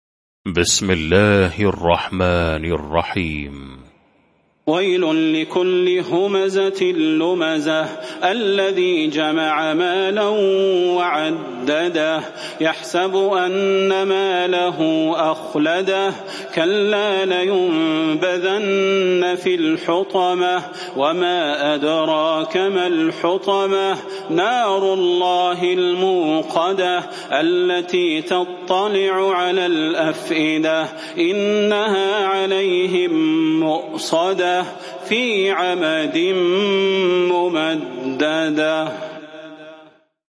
المكان: المسجد النبوي الشيخ: فضيلة الشيخ د. صلاح بن محمد البدير فضيلة الشيخ د. صلاح بن محمد البدير الهمزة